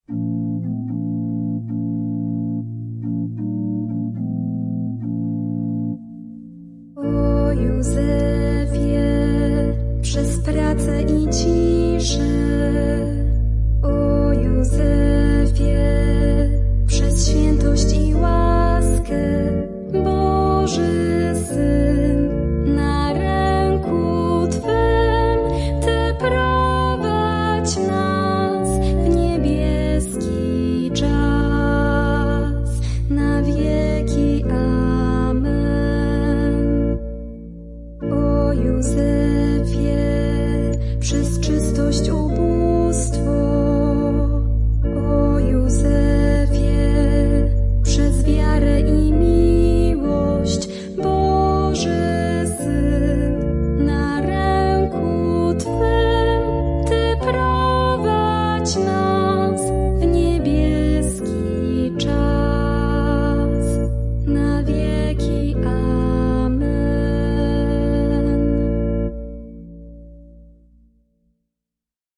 Śpiew ku czci św. Józefa